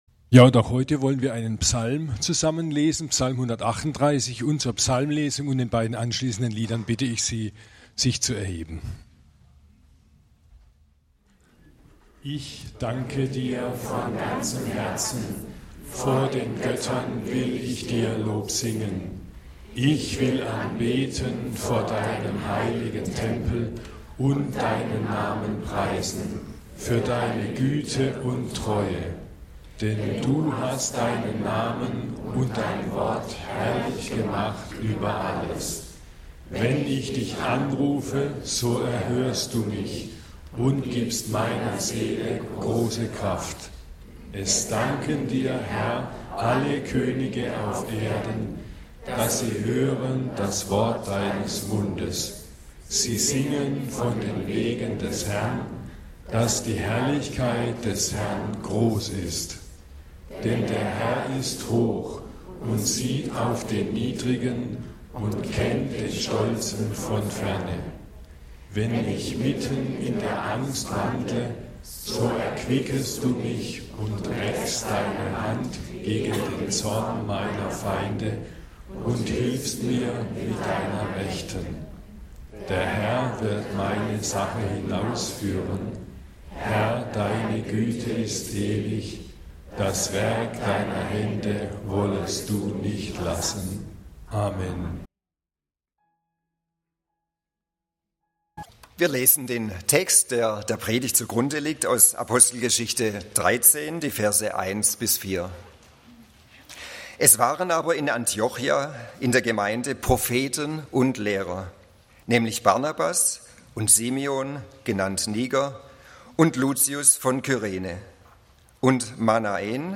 Paulus und seine Mitarbeiter: Erste Missionsreise, die Aussendung (Apg. 13, 1-4) - Gottesdienst